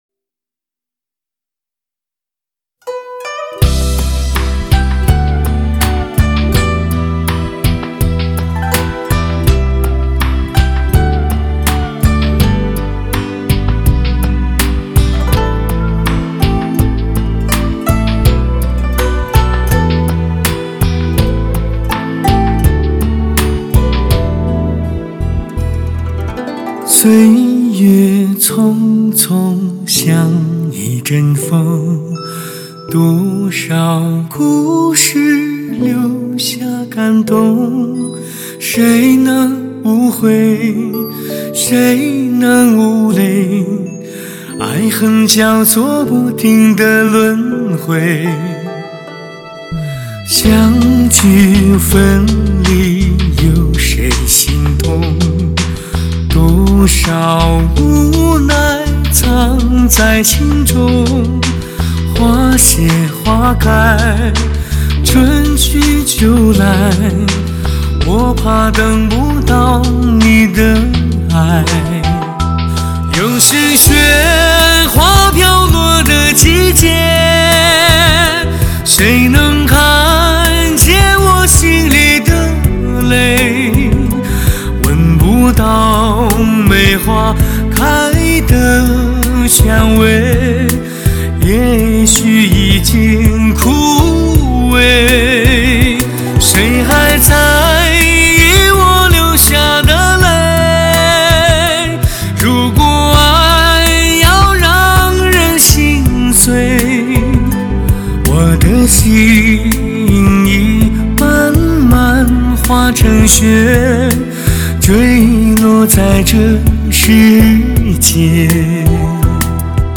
透明的音色脱俗的质感真抵灵魂。